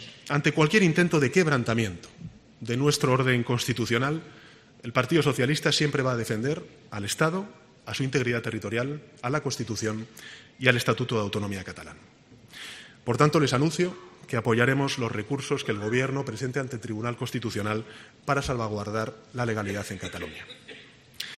Sánchez ha hecho este anuncio durante su intervención en el Foro Joly en Sevilla, en la que ha defendido que en Cataluña se tiene que abrir una legislatura constitucional, para lo que no puede haber al frente un presidente "inconstitucional"y hay que pasar la "página negra" de (Carles) Puigdemont.